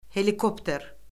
helik o pter